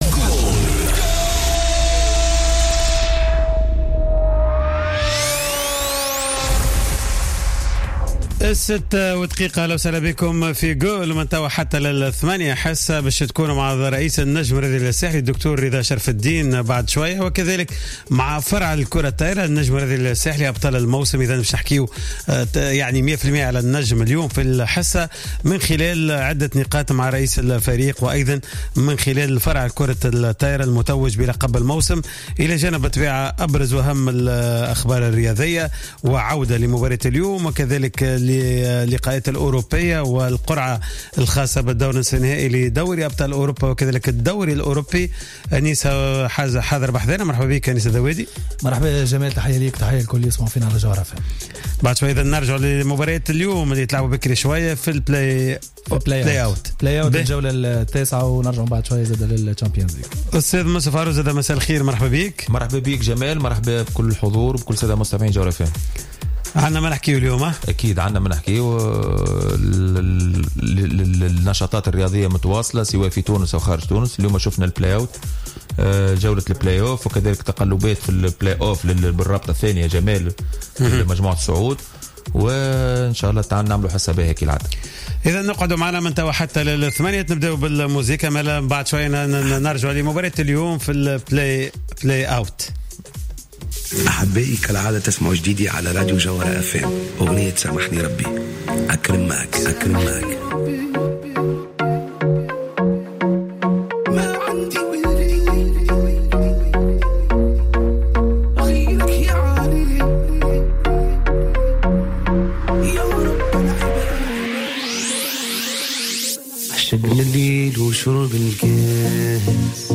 إستضافت حصة "قوول" ليوم الجمعة 21 أفريل 2017 رئيس النجم الرياضي الساحلي الدكتور رضا شرف الدين الذي تحدث عن عدة مواضيع التي تهم الوضعية الحالية للجمعية و من أهمها علاقة الفريق مع بقية الجمعيات و ملف الإثارة ضد النادي الصفاقسي بالإضافة إلى البرامج المستقبلية للنادي.